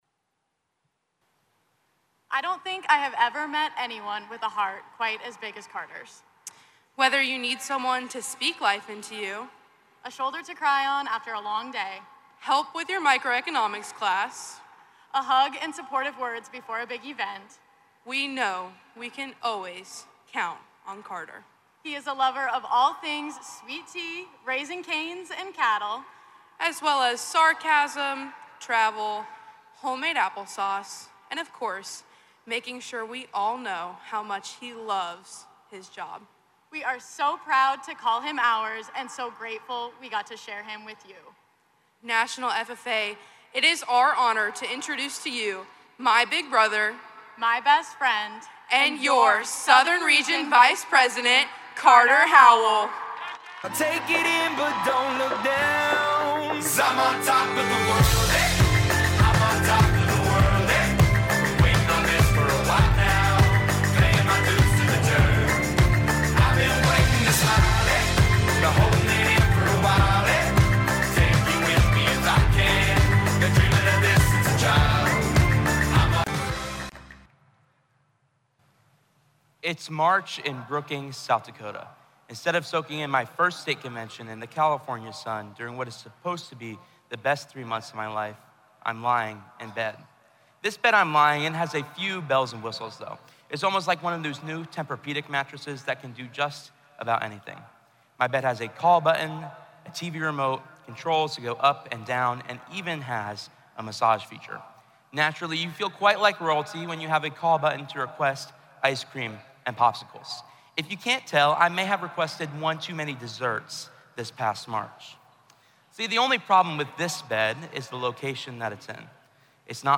retiring address to FFA members and guests at Session 4 of the National FFA Convention and Expo